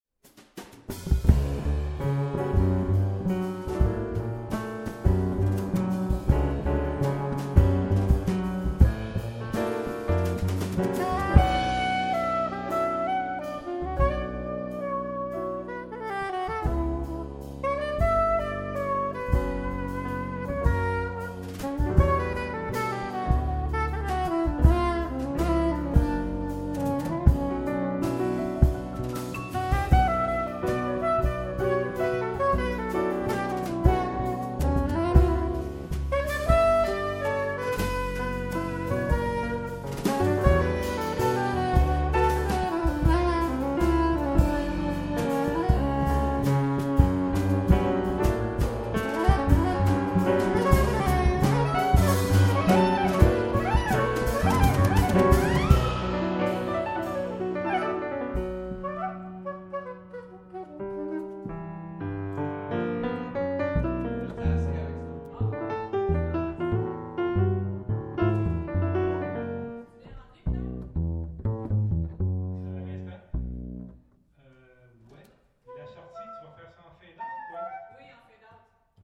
claviers / piano
saxophone
contrebasse
batterie et guitare